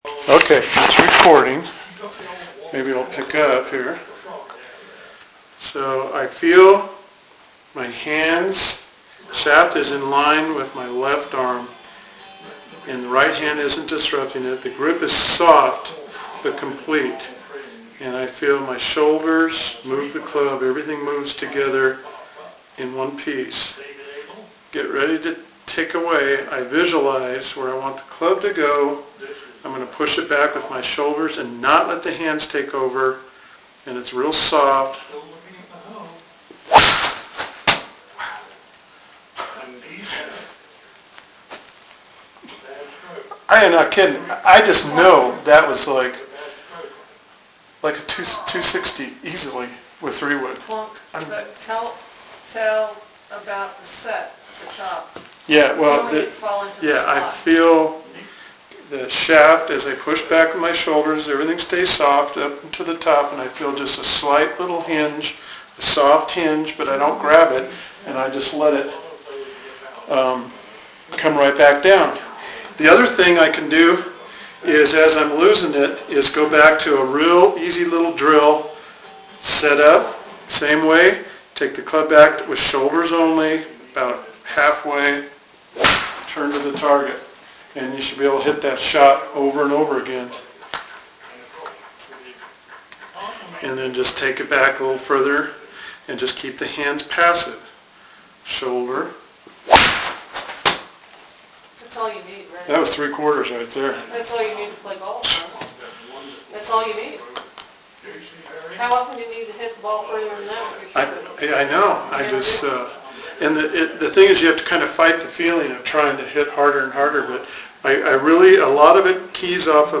Update:  I made these audio only recordings as I was working on my method for more reliability.  This was essentially taking audio notes as my method progressed.
After hitting 300 shots a day for over a Month (to get the hands out of the entire swing)… WOW, listen how SOLID – 3 wood off the floor no tee, no mat into a net.